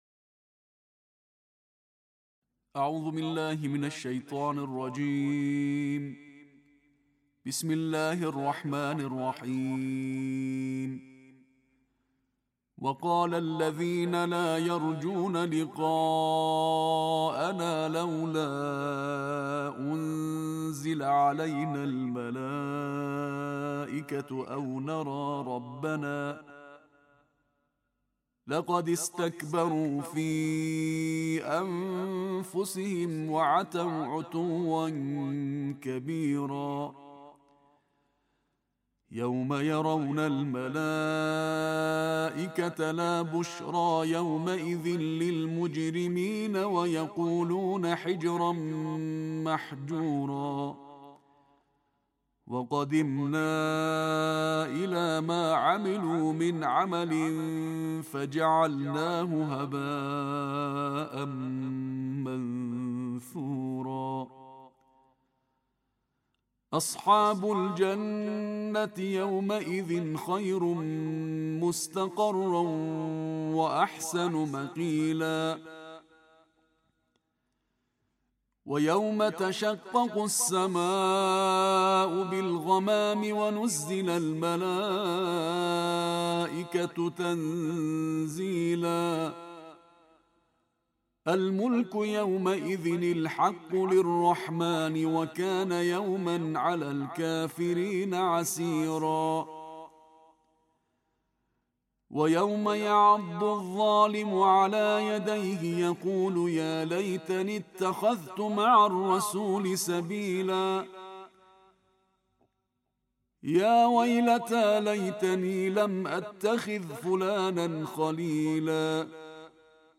Todos os dias com o Alcorão: Recitação Tarteel de Juz 19